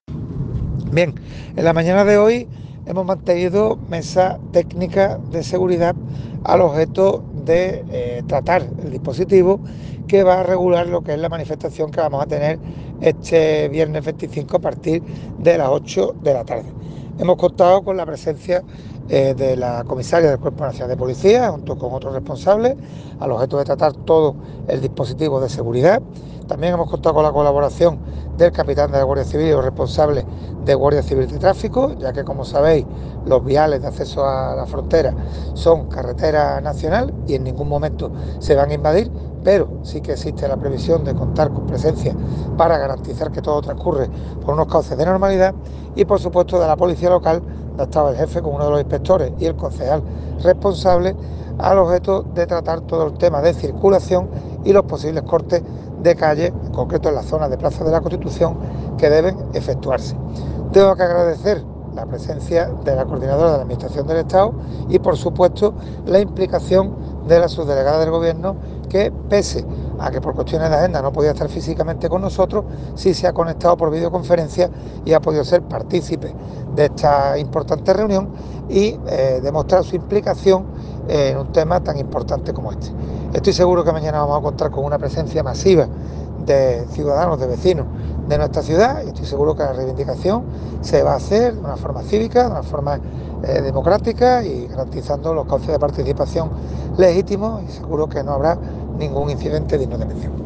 Audio al respecto del alcalde, Juan Franco: